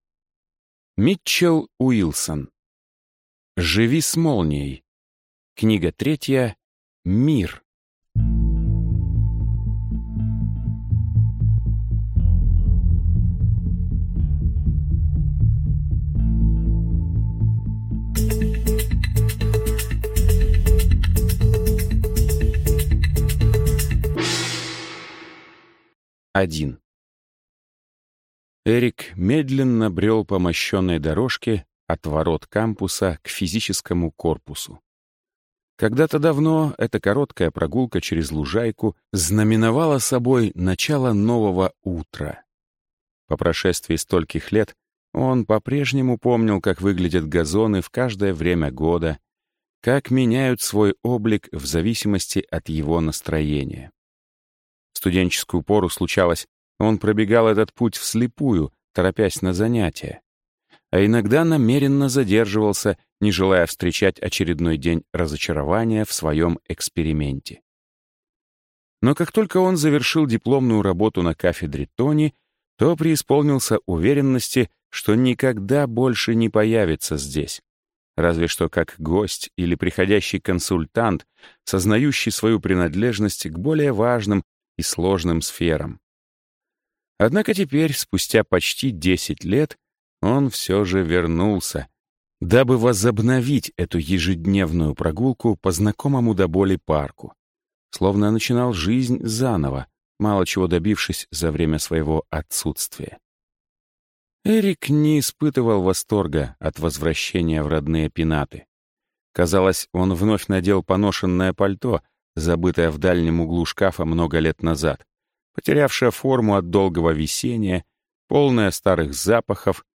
Аудиокнига Живи с молнией.